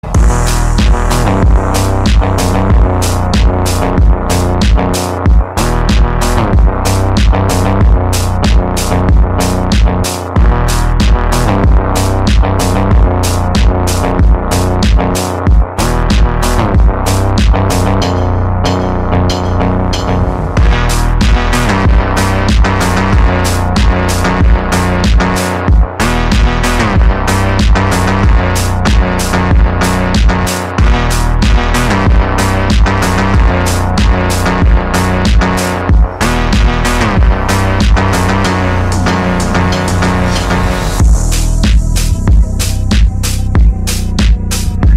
мощные басы
phonk
Hot Phonk track